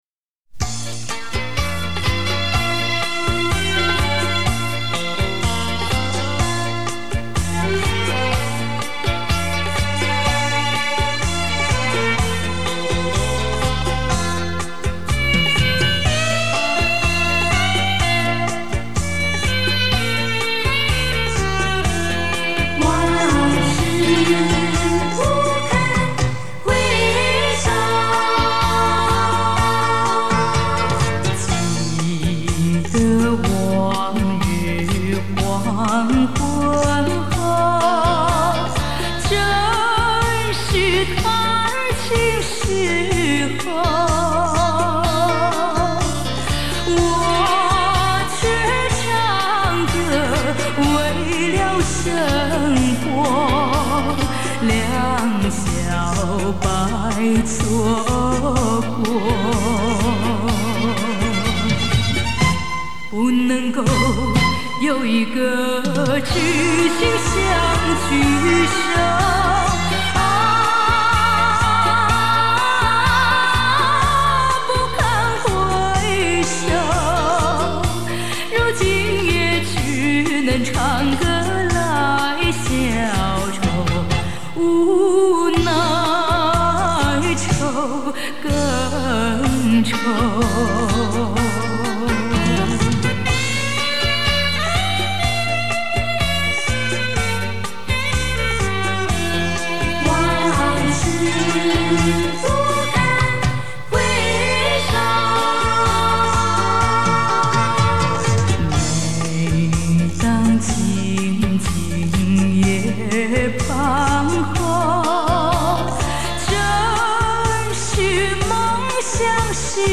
音频采集：JAPAN先锋卡座T-3